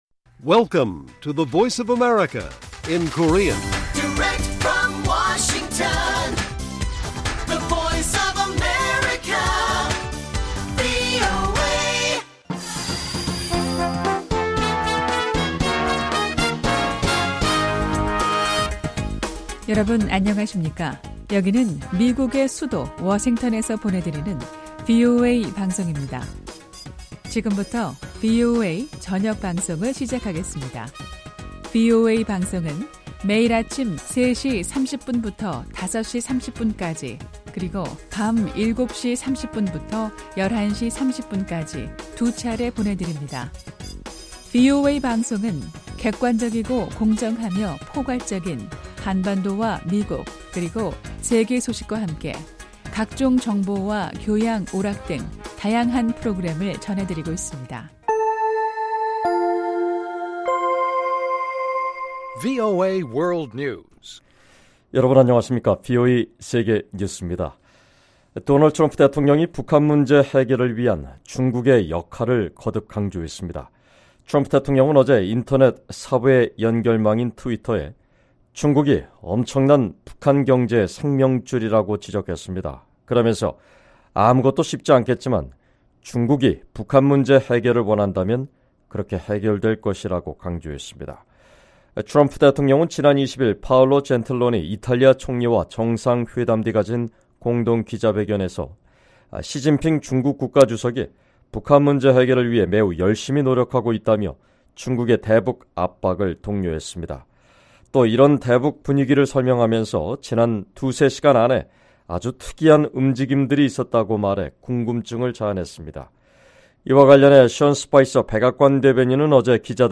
VOA 한국어 방송의 토요일 오후 프로그램 1부입니다.